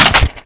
weap_med_gndrop_3.wav